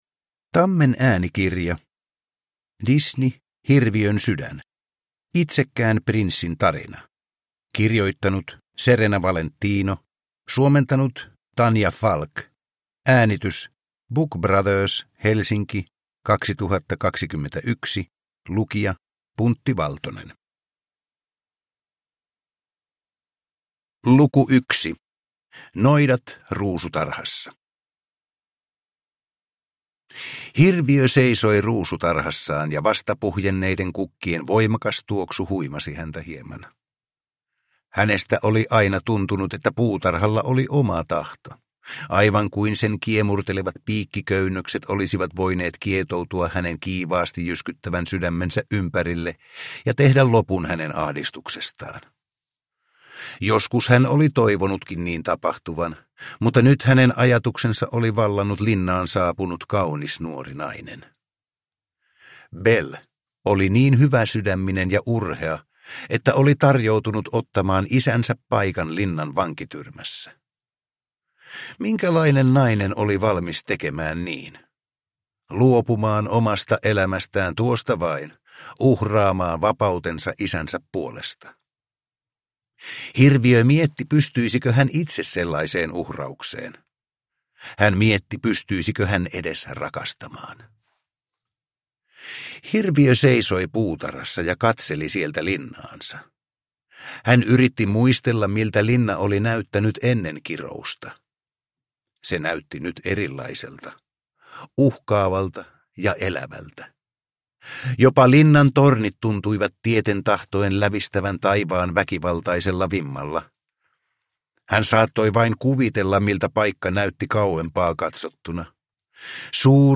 Hirviön sydän – Ljudbok – Laddas ner